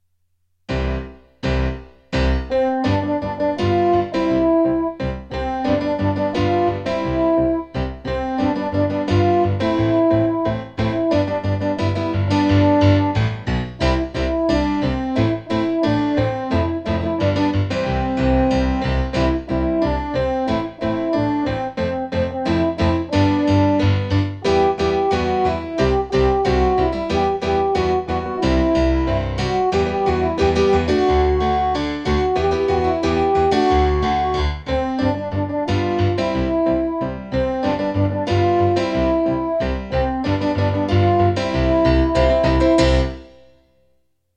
Just like in class, every recording will start with three piano chords to get ready before the melody starts and you can sing along (or simply follow along reading the score).  I used a different “instrument” from my keyboard’s sound library for each melody.